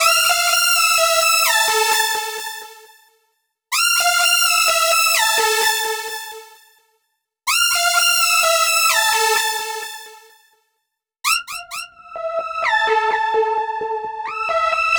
Session 11 - Lead 04.wav